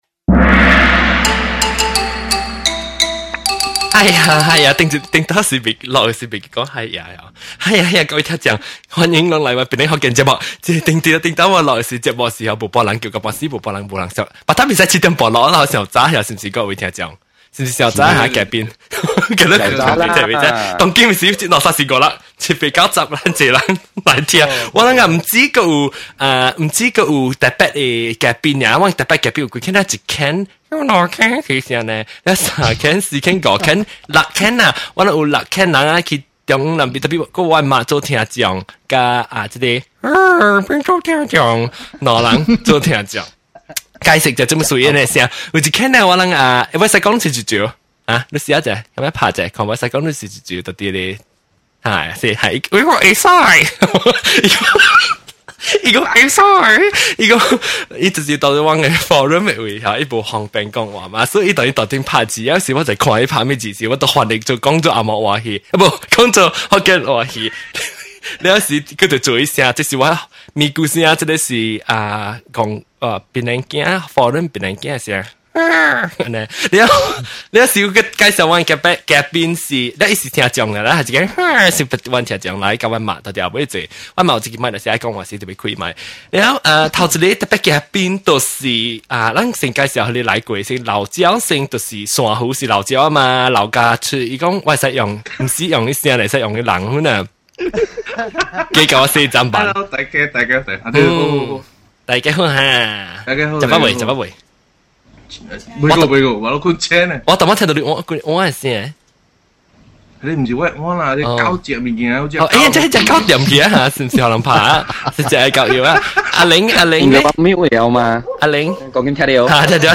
In the middle of the chat, a few laughs are unavoidable.